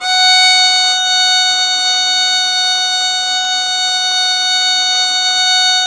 Index of /90_sSampleCDs/Roland - String Master Series/STR_Violin 4 nv/STR_Vln4 no vib
STR VLN BO0G.wav